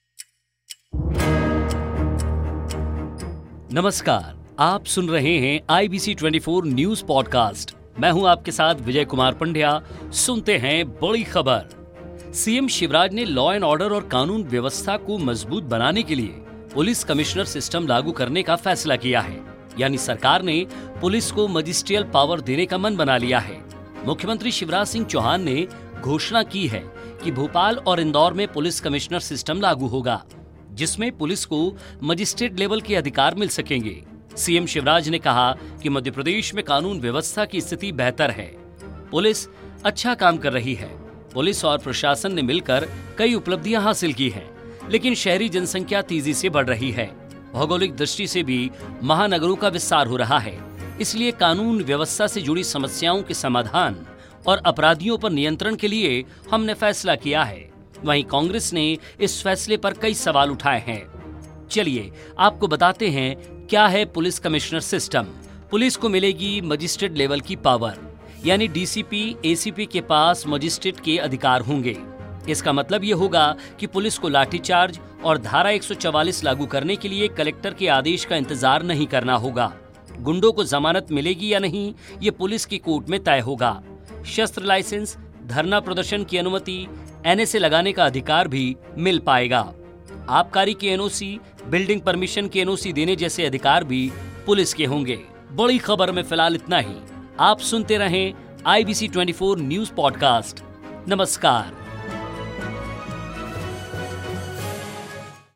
आज की बड़ी खबर
Today's big news